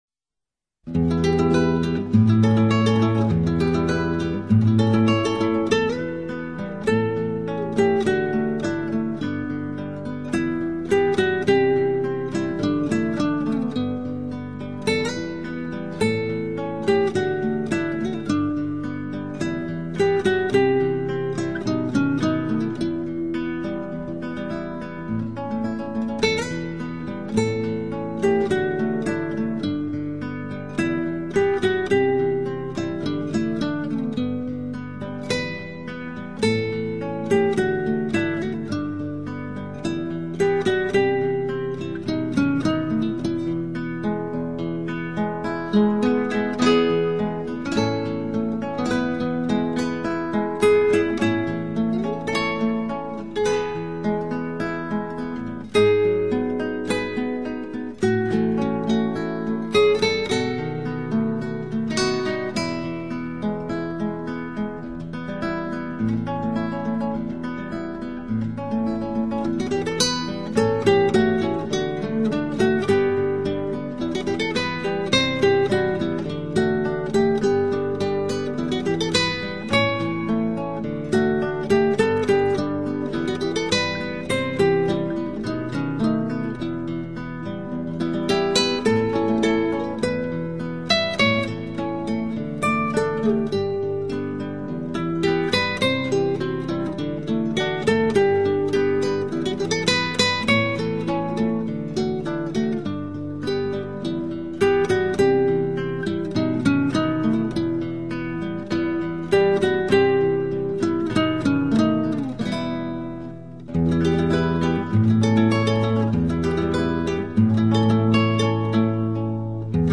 此专辑为吉他休闲小品集，里面收录的都是经典旧曲，历久而弥新，很适合 在夜晚静心聆听... ...